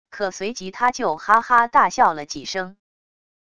可随即他就哈哈大笑了几声wav音频生成系统WAV Audio Player